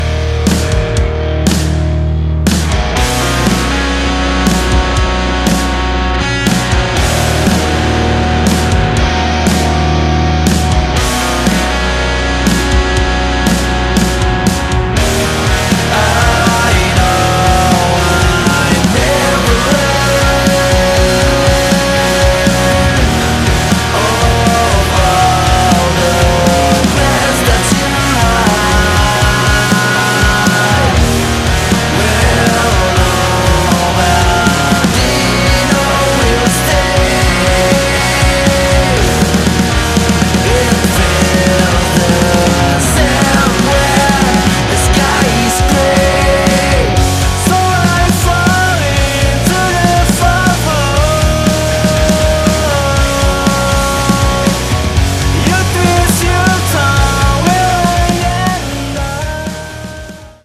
unit alternative rock, grunge & shoegaze asal Kota Depok